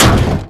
car_heavy_7.wav